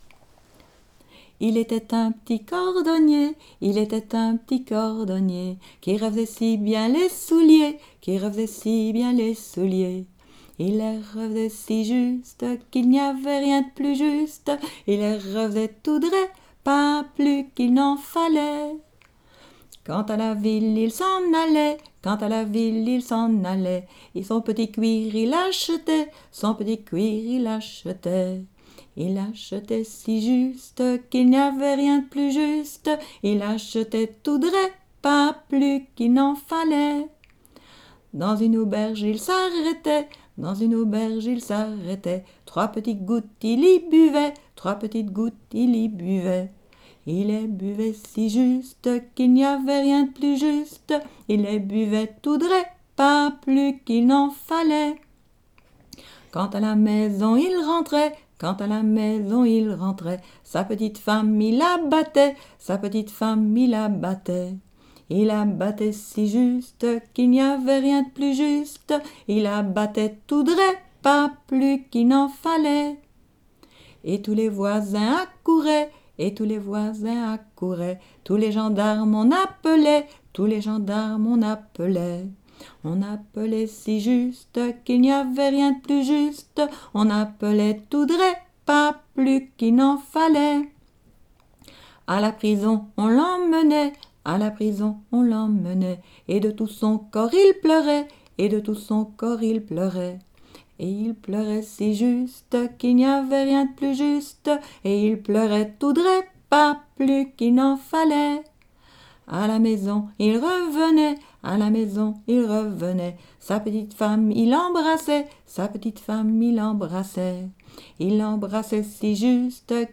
Genre : chant
Type : chanson narrative ou de divertissement
Aire culturelle d'origine : Haute Ardenne
Lieu d'enregistrement : Malmedy